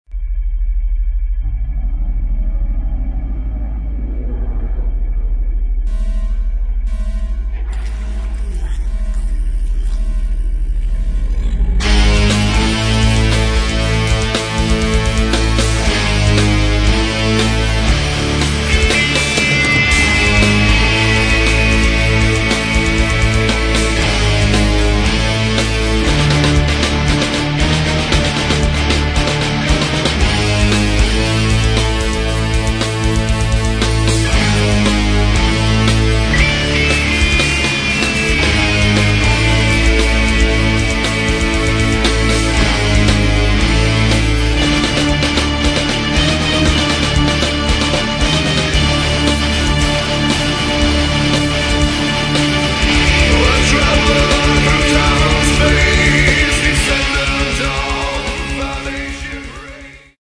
Metal
вокал, гитары
бас
клавишные
ударные